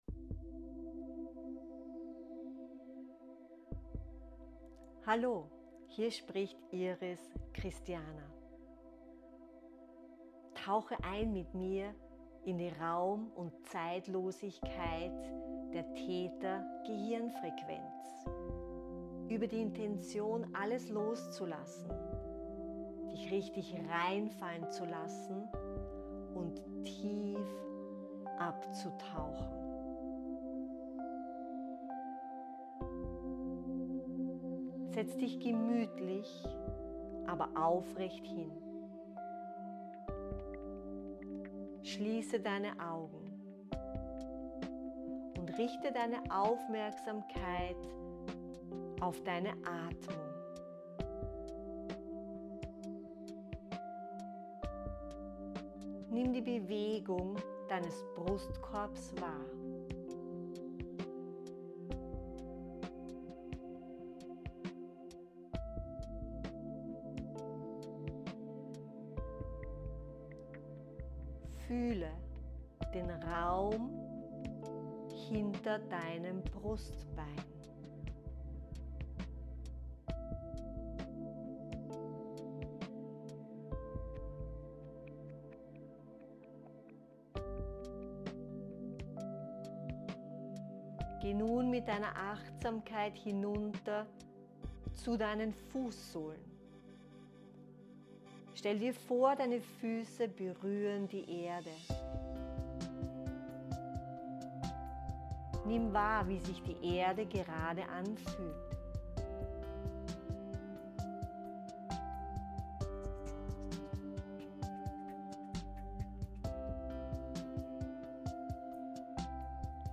Meditation in die Theta-Gehirnfrequenz